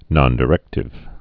(nŏndĭ-rĕktĭv, -dī-)